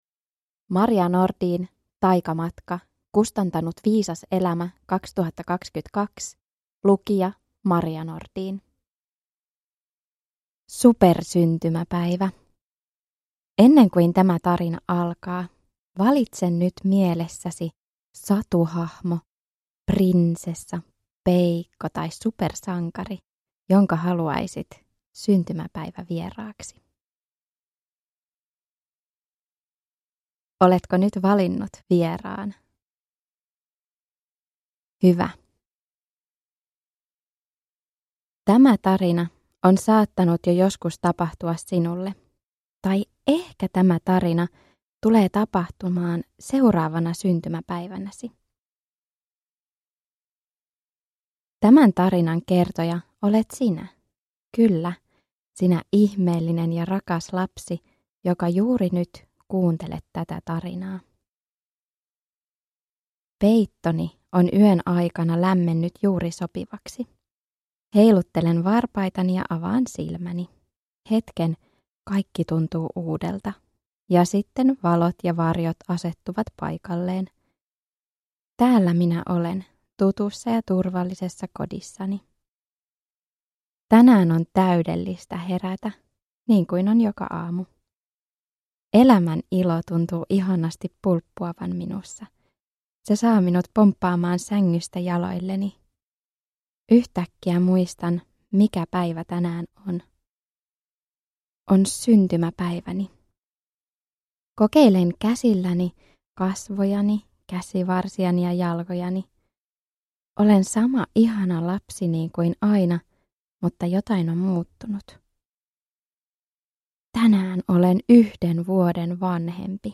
Taikamatka – Ljudbok – Laddas ner